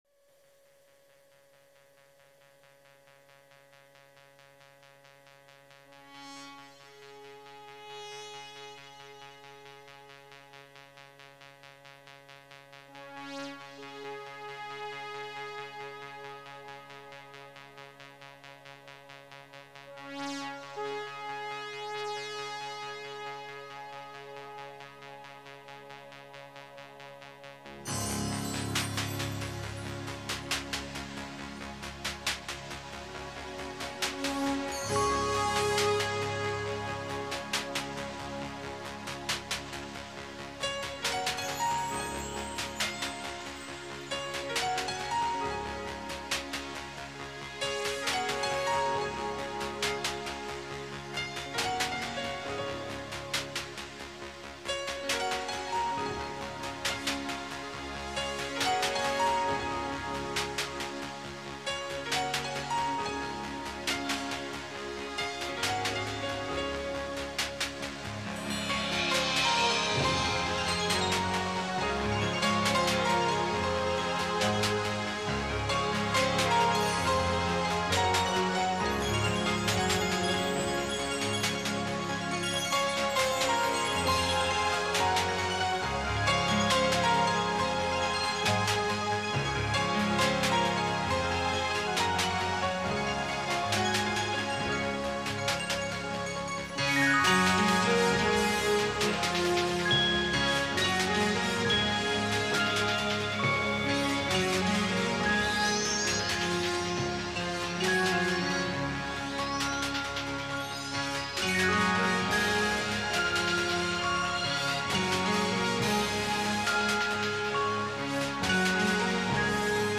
instrumental theme